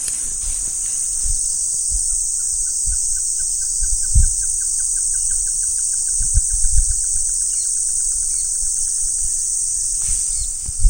Great Antshrike (Taraba major)
Country: Argentina
Province / Department: Corrientes
Condition: Wild
Certainty: Recorded vocal